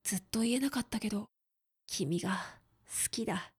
クール男性
dansei_zuttoienakattakedokimigasukida.mp3